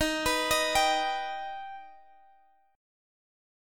Listen to D#+ strummed